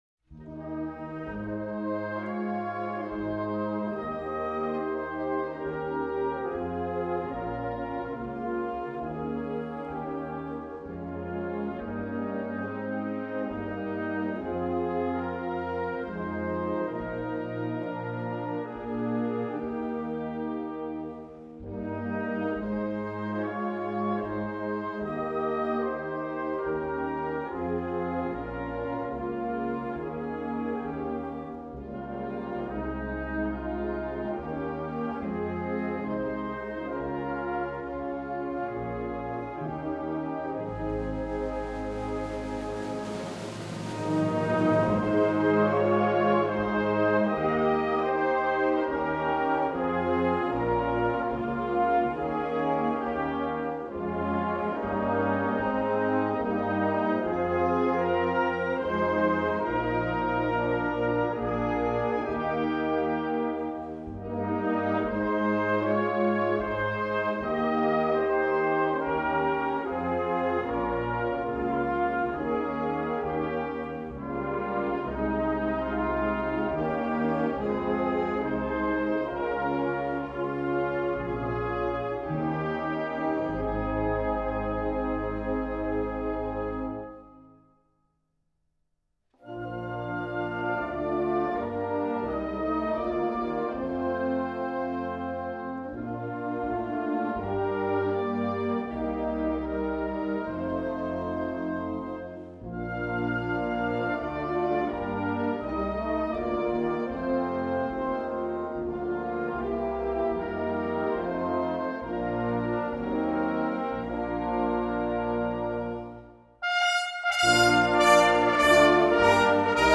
I couldn’t find the exact versions of the Hymns from the funeral so I have some different renditions by military bands, which are just as nice.